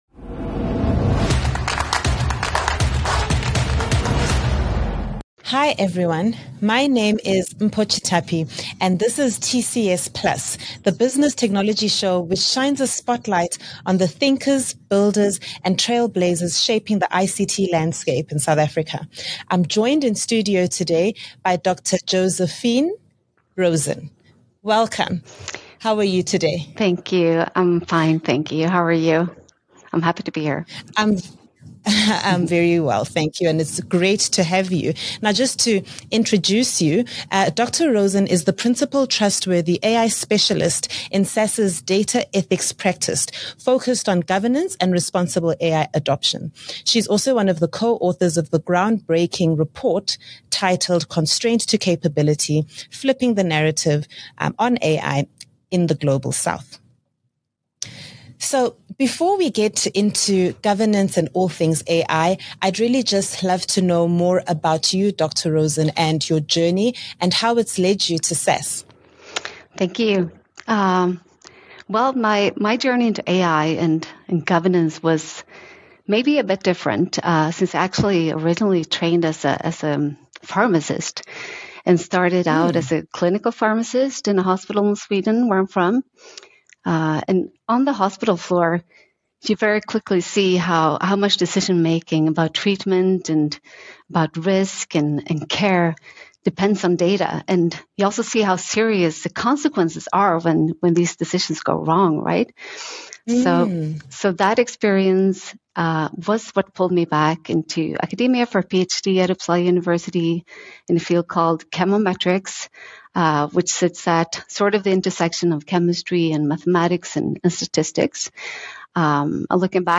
What unfolds is a rich conversation that challenges long-held assumptions about Africa's role in the global AI ecosystem — and reframes governance, ethics and constraint not as obstacles but as strategic advantages.